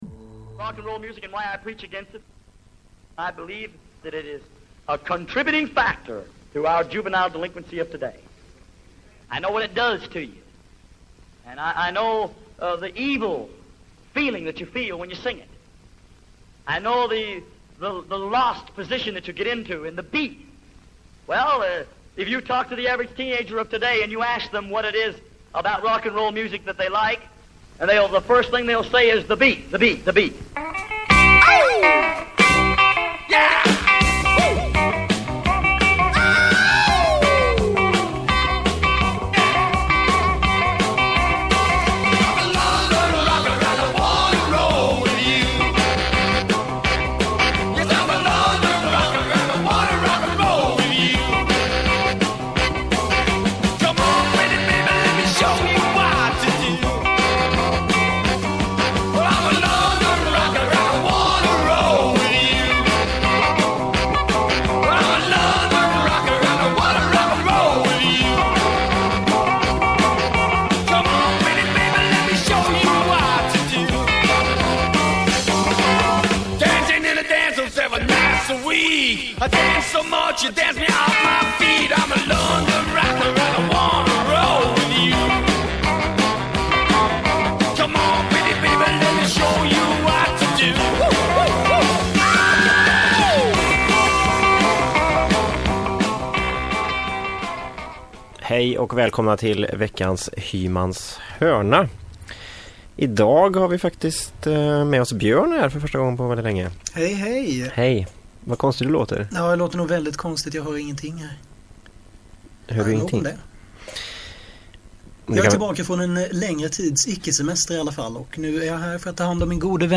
Hymans H�rna handlar om rock n' roll, hela v�gen fr�n country och blues fram till punk och h�rdrock. Det blir sprillans nytt s�v�l som knastrande gammalt, varvat med intervjuer och reportage fr�n G�teborgs musikv�rld.